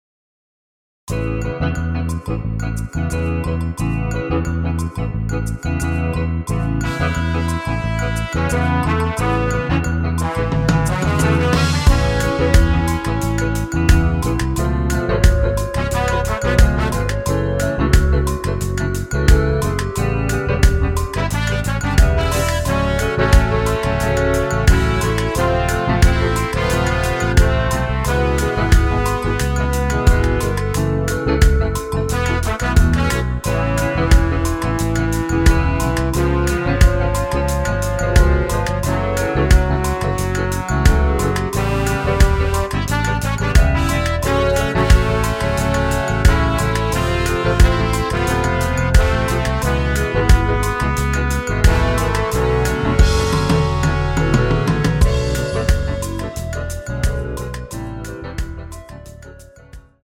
원키에서(-7)내린 멜로디 포함된 MR입니다.
엔딩이 길어 축가에 사용 하시기 좋게 엔딩을 짧게 편곡 하였습니다.(원키 코러스 버전 미리듣기 참조)
앞부분30초, 뒷부분30초씩 편집해서 올려 드리고 있습니다.